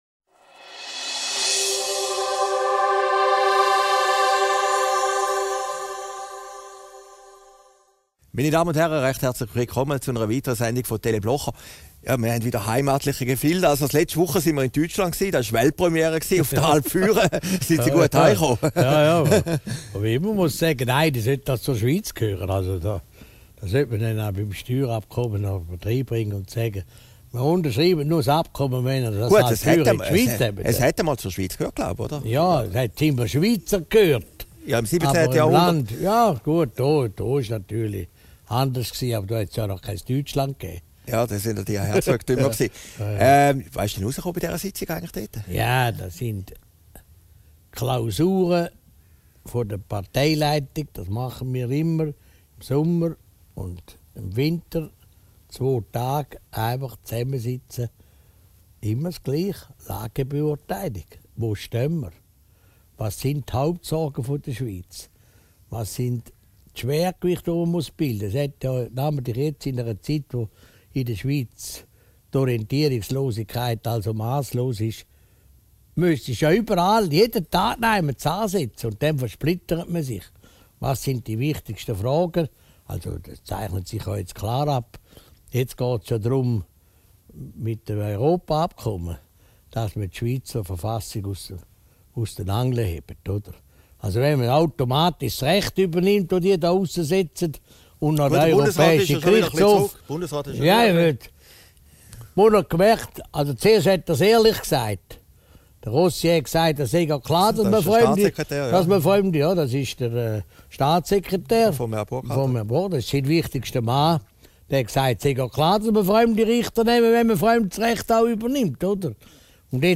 Aufgezeichnet in Herrliberg, 12. Juli 2013